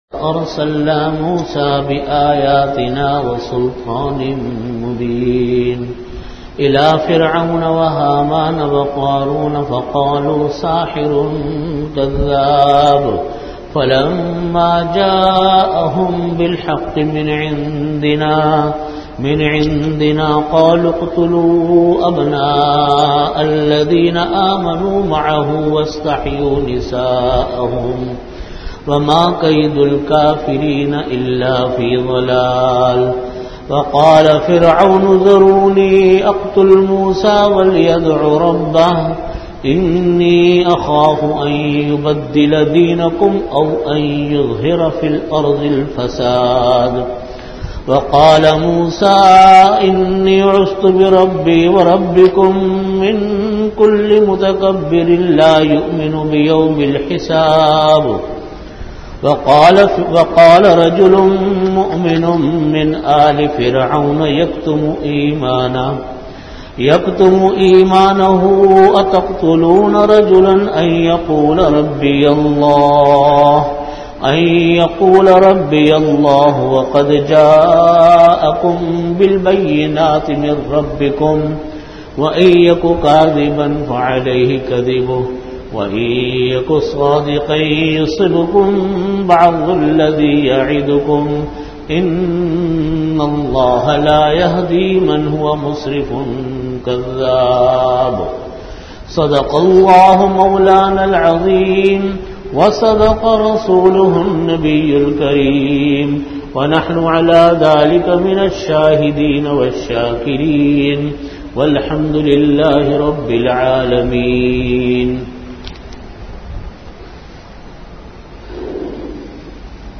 Time: After Asar Prayer Venue: Jamia Masjid Bait-ul-Mukkaram, Karachi